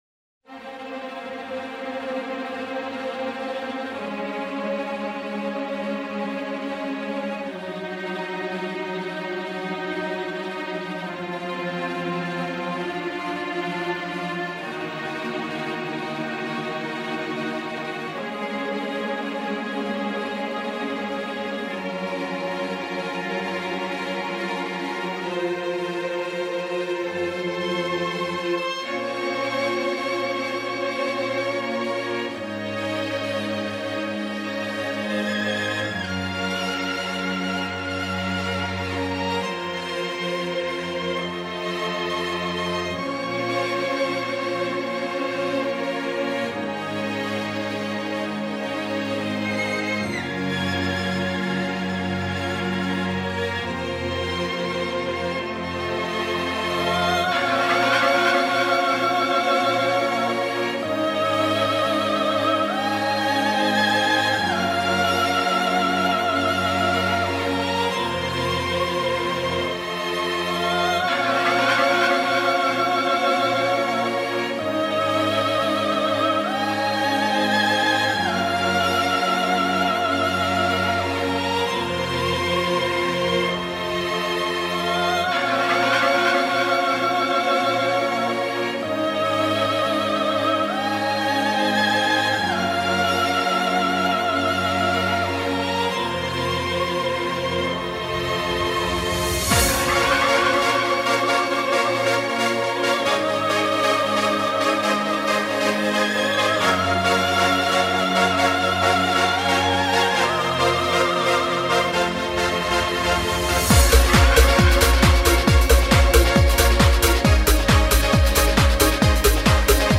this is the pre-game and tunnel music played at Elland Road